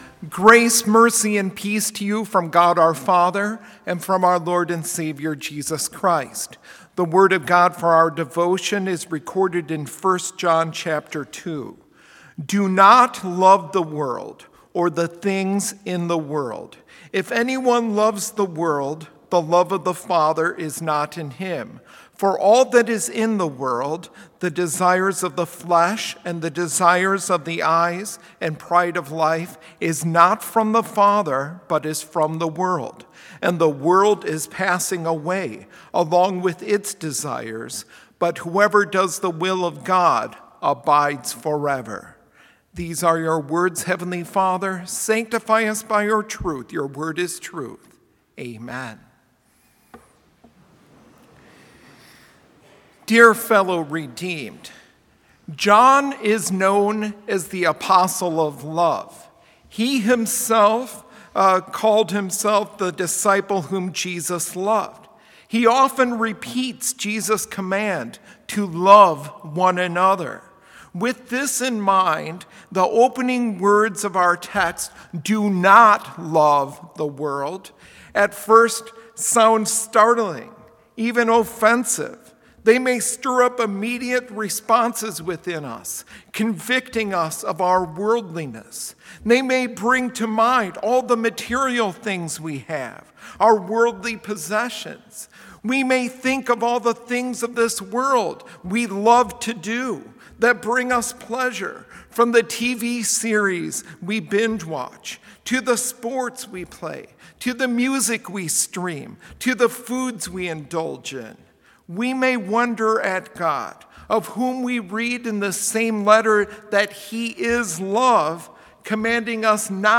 Complete service audio for Chapel - November 10, 2021
Complete Service